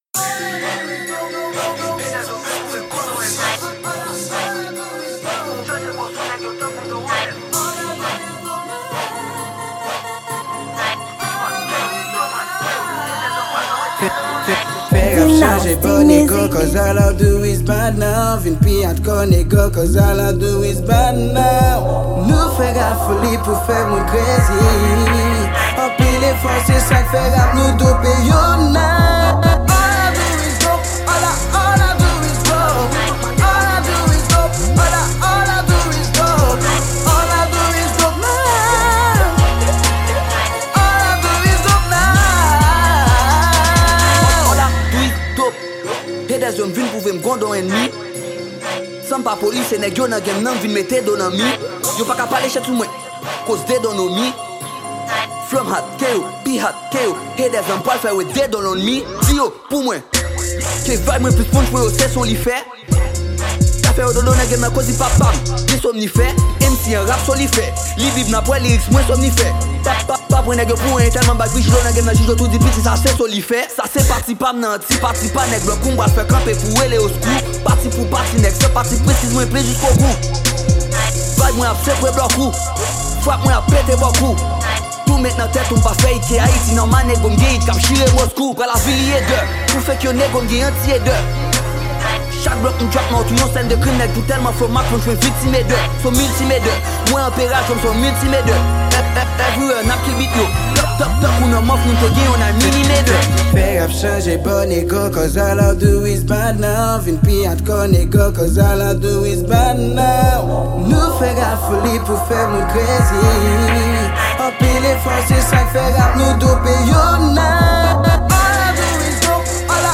Genre: Rap-Rnb.